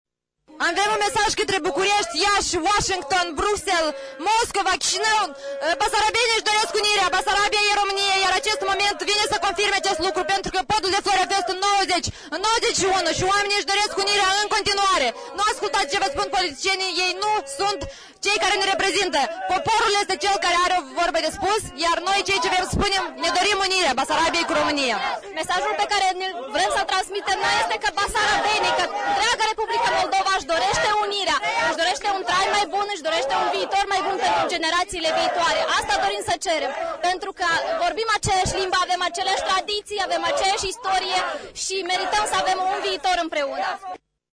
Tinerii unionişti au fost întâmpinaţi în Piaţa Universităţii cu aplauze de câteva sute de bucureşteni, susţinători ai unirii României cu Basarabia. În Piaţa Universităţii s-au cântat Imnul naţional al României şi Hora Unirii.